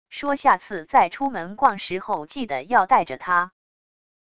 说下次再出门逛时候记得要带着她wav音频生成系统WAV Audio Player